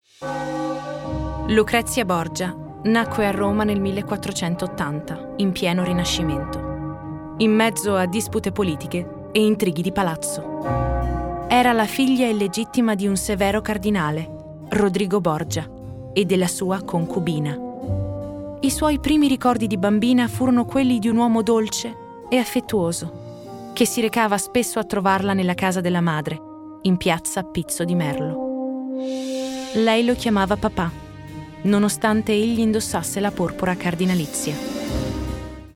Demo głosowe